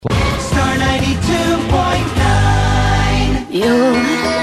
Here is a short jingle